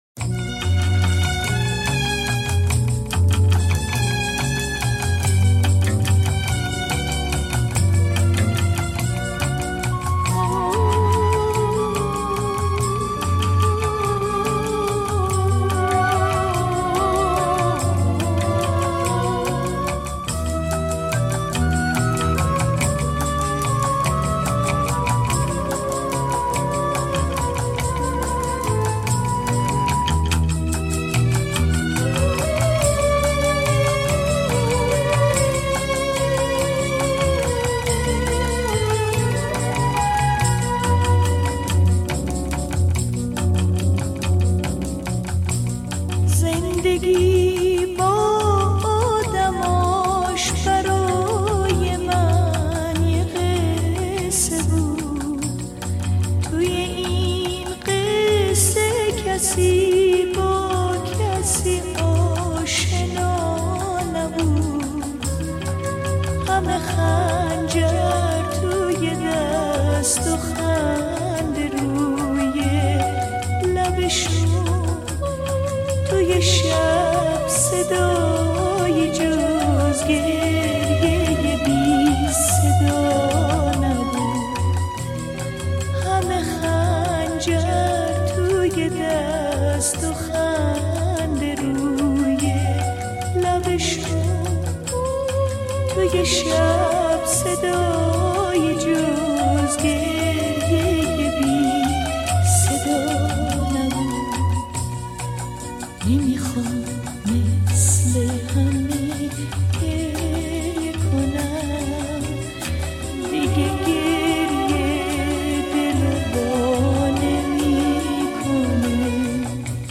Iranian songstress